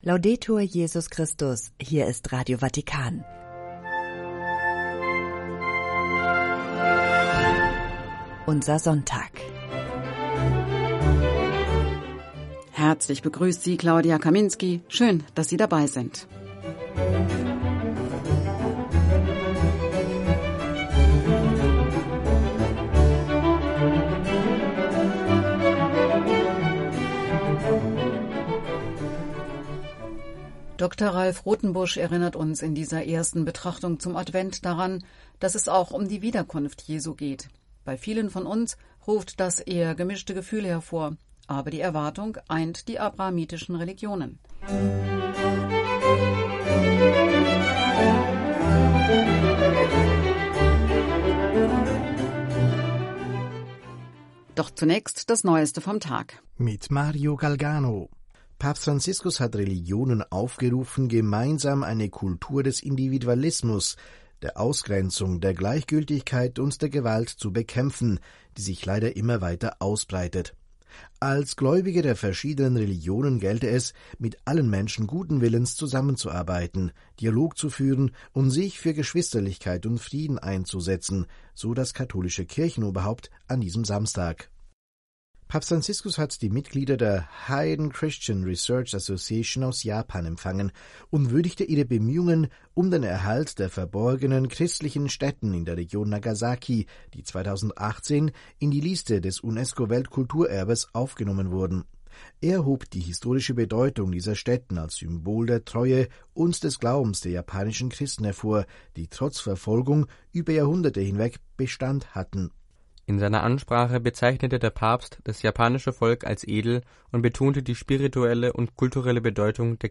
Entdecke, höre und lade die Podcasts herunter unserer Sendung Treffpunkt Weltkirche, dem Nachrichtenjournal von Vatican News und höre deine Lieblingssendungen, wann immer du willst.